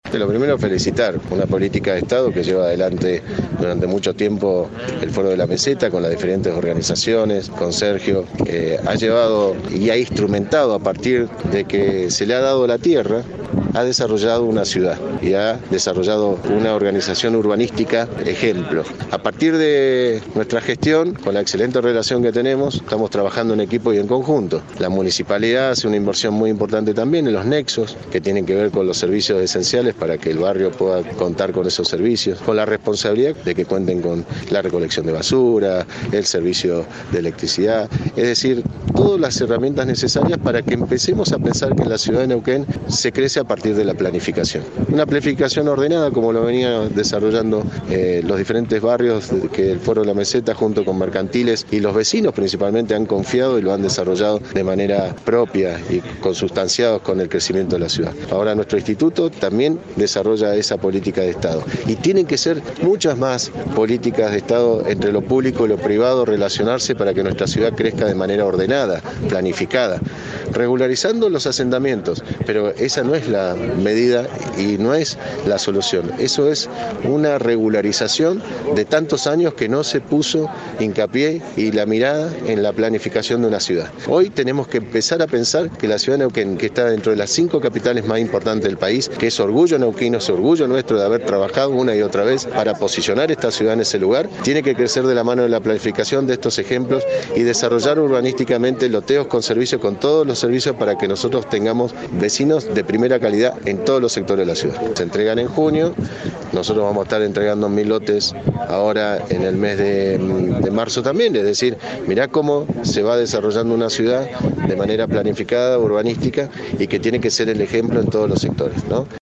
Mariano Gaido, Intendente.